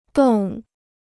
冻 (dòng): to freeze; aspic or jelly.